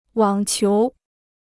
网球 (wǎng qiú) Free Chinese Dictionary